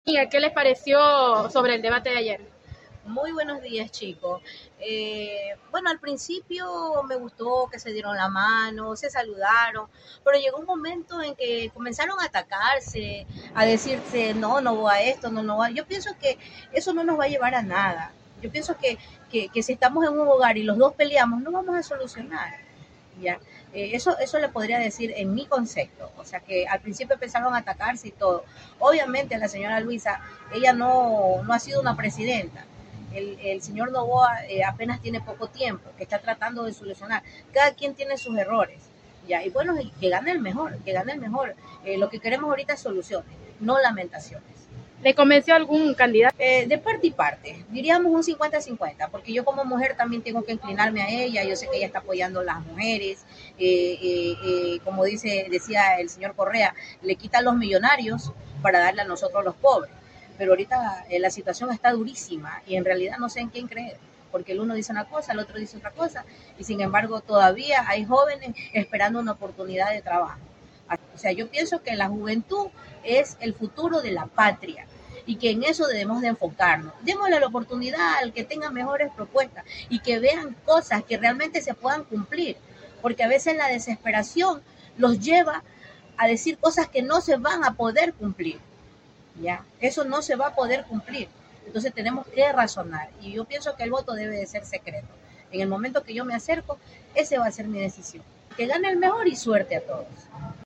Algunos ciudadanos de Quevedo expresaron su descontento por la falta de propuestas claras y la abundancia de acusaciones entre los dos candidatos, la mayoría vinculadas a casos de corrupción y narcotráfico.
Una comerciante, que prefirió no revelar su nombre, criticó que el debate se centrara más en discusiones que en la presentación de propuestas.
Entrevista-opinion.mp3